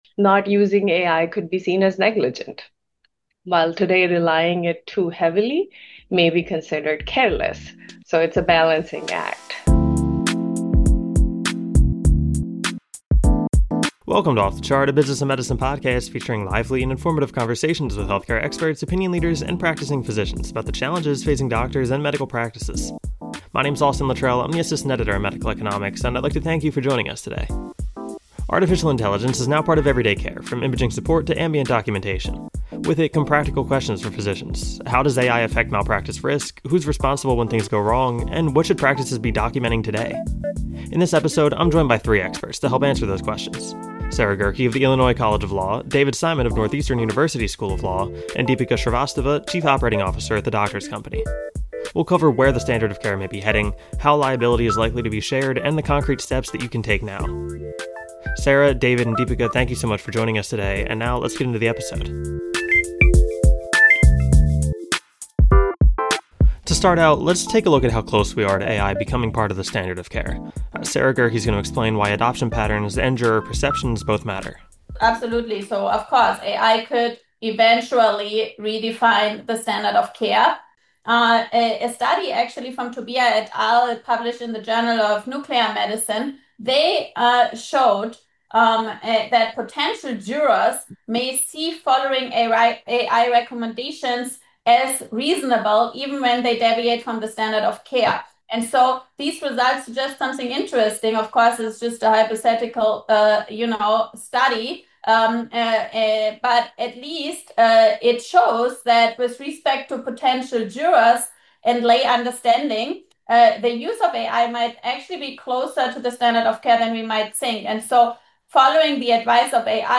Artificial intelligence (AI) is entering everyday care, so of course it’s raising questions about malpractice. In this episode, we sat down with three national experts shaping how AI liability will evolve